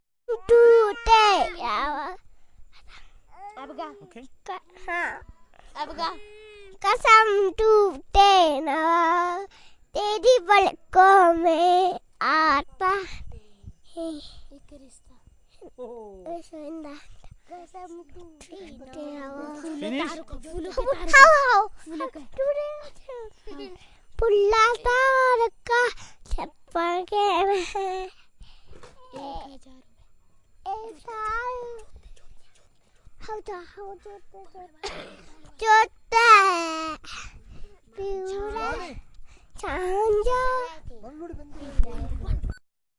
描述：在印度达姆萨拉录制的2002年。孩子们演唱电影中的歌曲和儿童歌曲。
Tag: 宝莱坞 印度 孩子